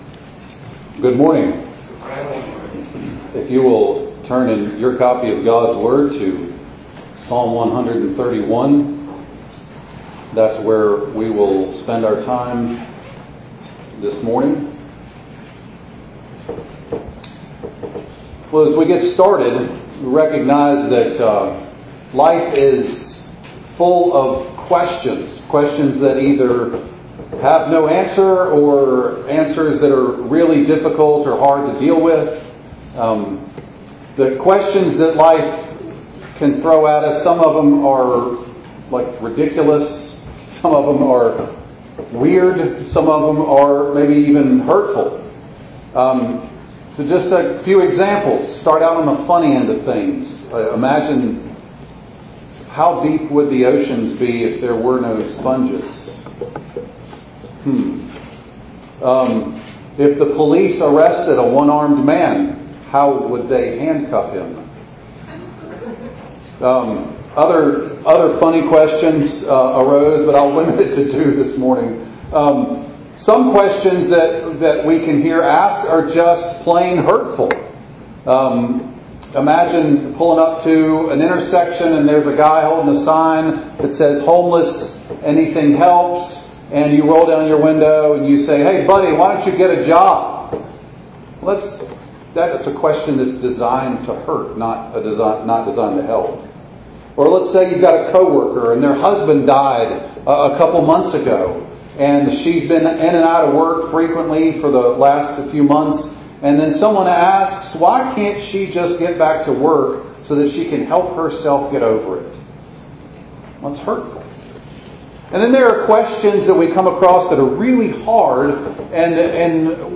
7_27_25_ENG_Sermon.mp3